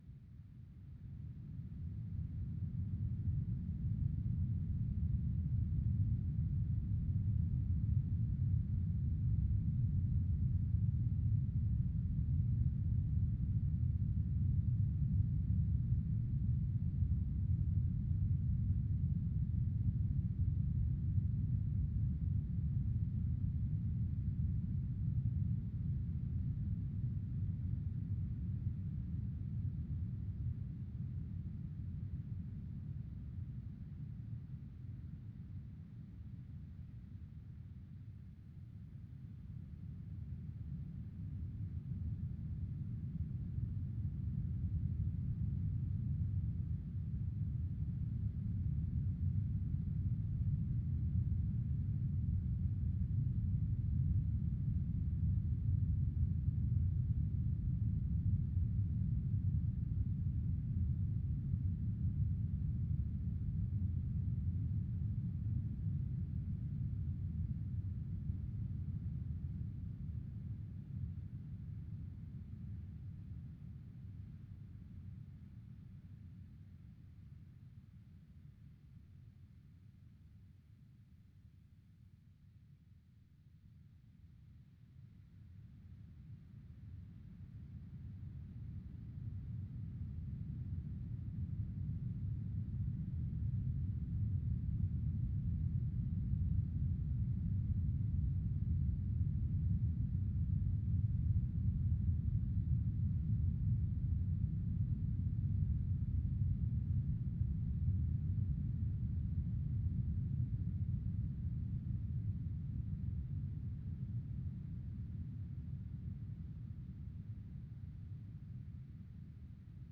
Вложения Море - генератор шума - ст.mp3 Море - генератор шума - ст.mp3 9,8 MB · Просмотры: 1.737